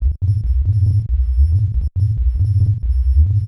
ALIASING B-L.wav